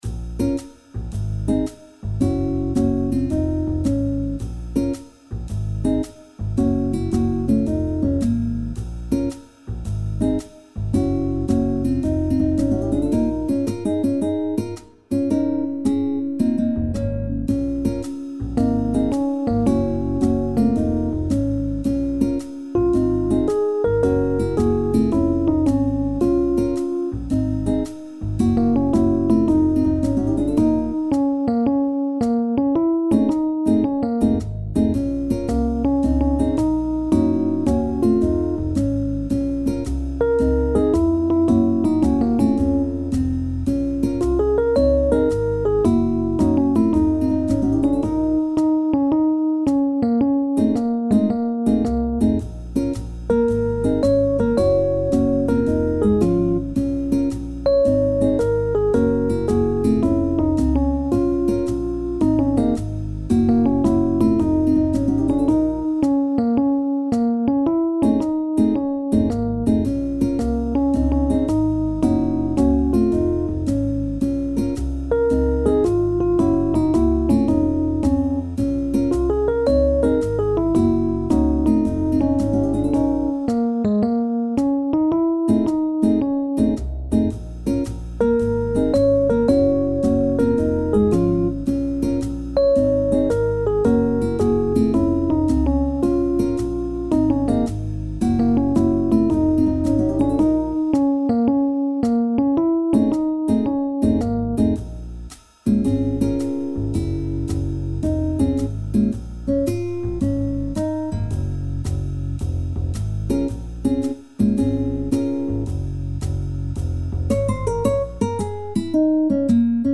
SSSAAA met sopraan-solo | SSSAAB met sopraan-solo